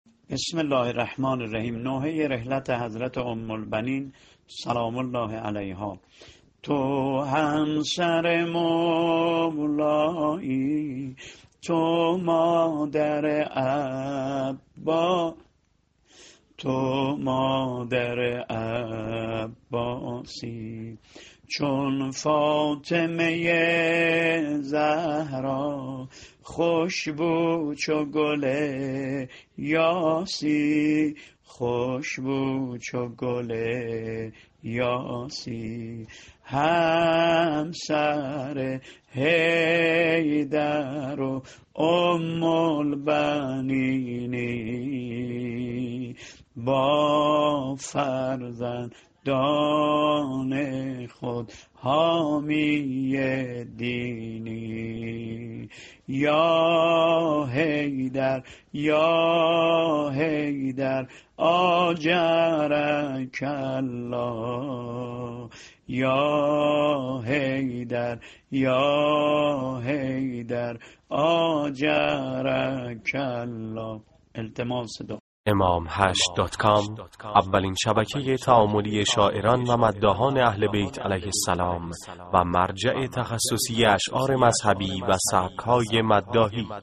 متن شعرو نوحه رحلت حضرت ام البنین سلام الله علیها -(تو همسر مولایی( تو مادر عباسی)۲)